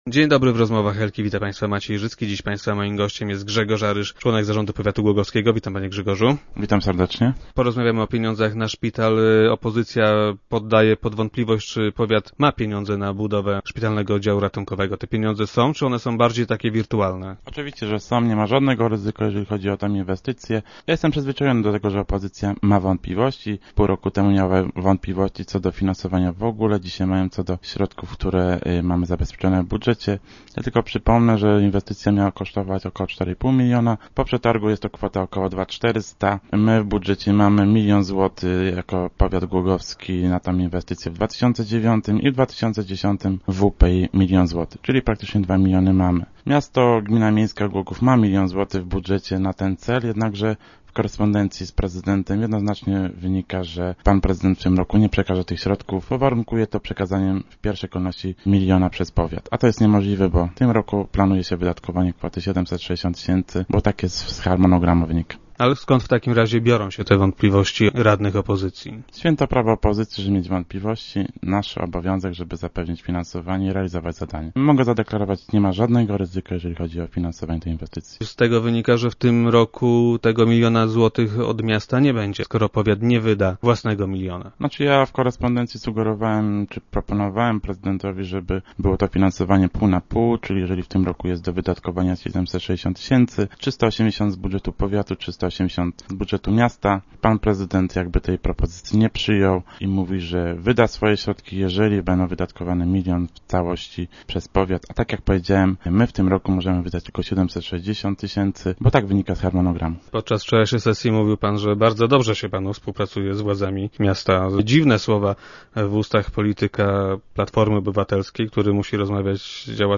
Dziś mają wątpliwości dotyczące zabezpieczenia pieniędzy na ten cel w powiatowym budżecie. Jak stwierdził Grzegorz Aryż, członek zarządu powiatu głogowskiego i dzisiejszy gość Rozmów Elki, problemów z pieniędzmi na tą inwestycję nie ma.